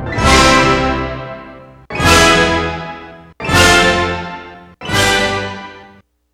brassup.wav